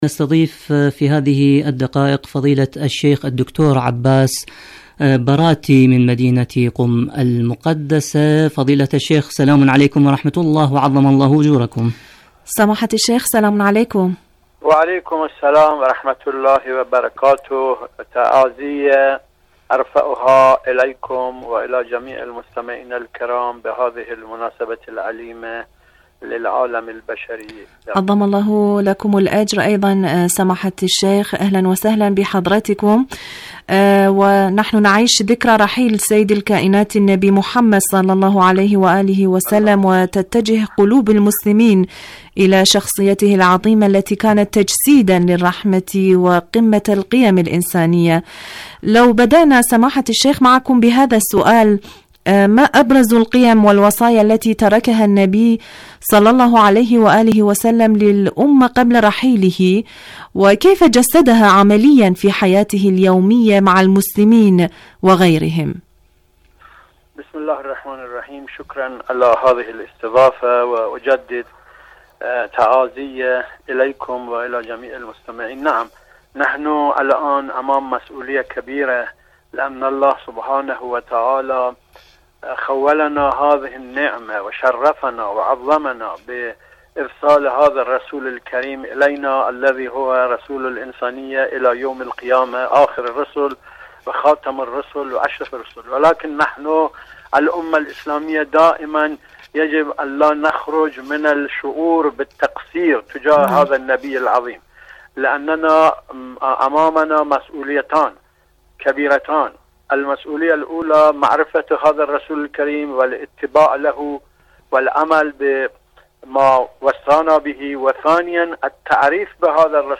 إذاعة طهران العربية مقابلات إذاعية برنامج عروج النورين